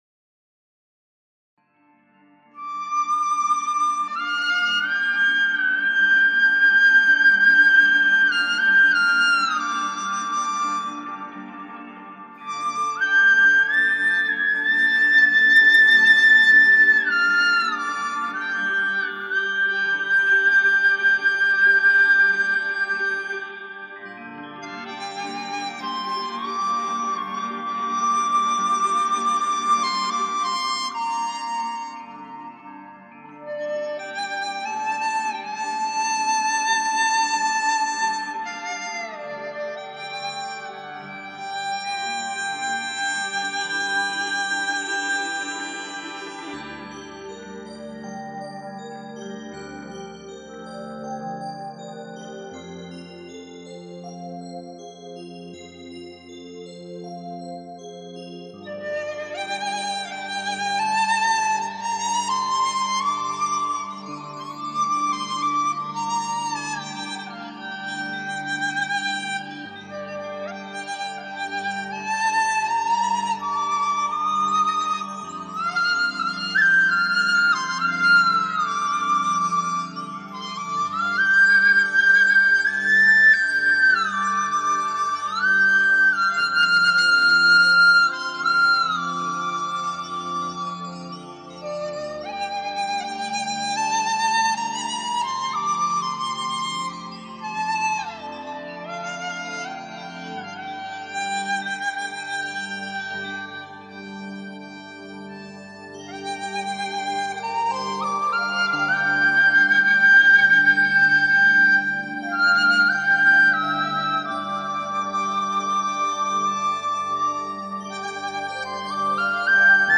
笛子独奏曲